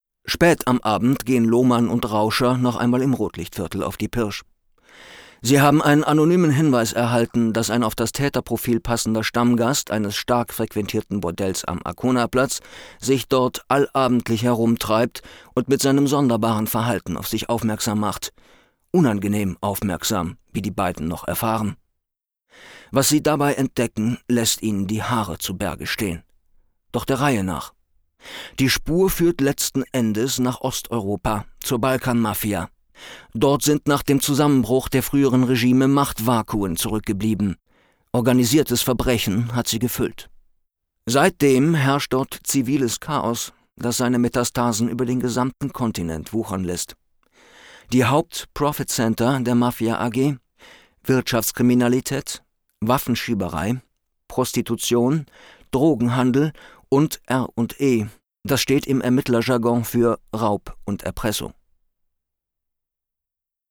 Die Hörproben im Demobereich dienen der Präsentation meiner Bandbreite als Sprecher für Funk TV und Multimedia.
Sprechprobe: eLearning (Muttersprache):